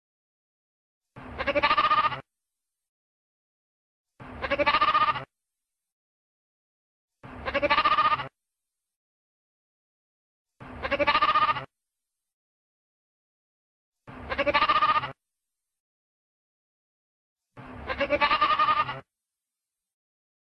Sonidocabramontés.mp3